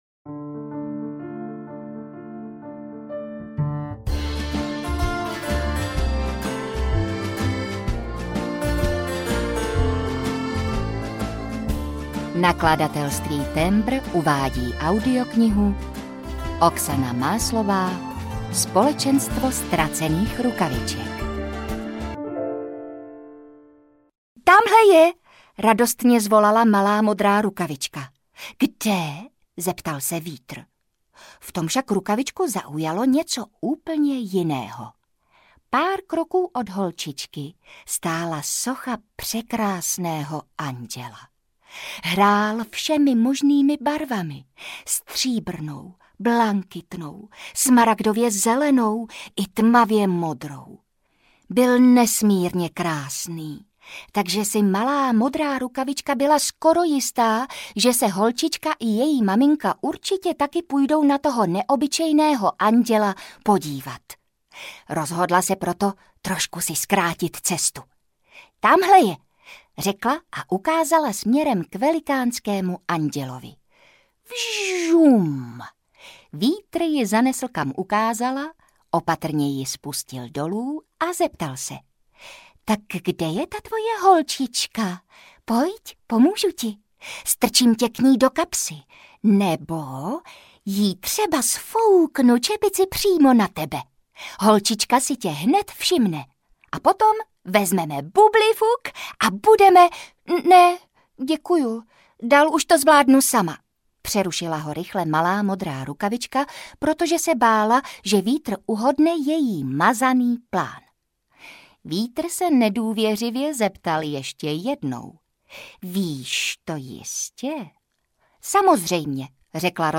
Ukázka z knihy
spolecenstvo-ztracenych-rukavicek-audiokniha